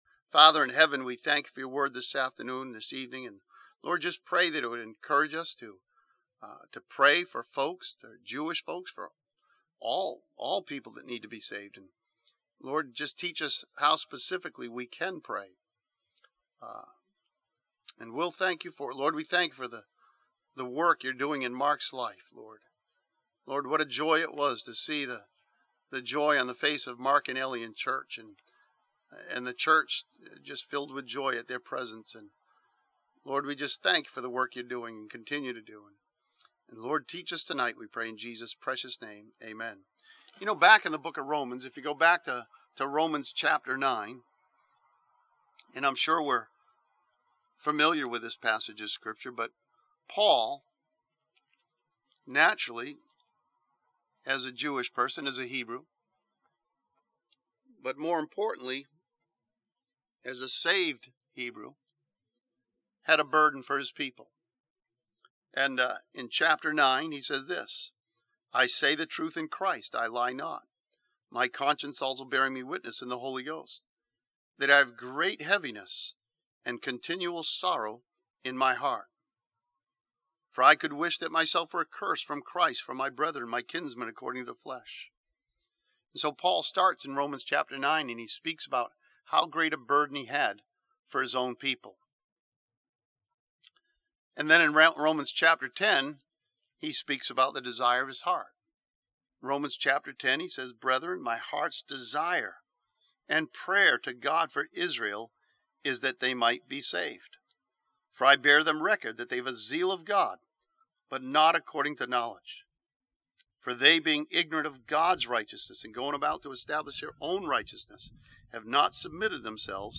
This sermon from 1 Corinthians chapter 3 studies the potential and possibilities of preaching the Gospel to the Jewish people.